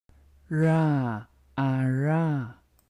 English: Pronunciation of a retroflex approximant, [ɻ]: [ɻa aɻa].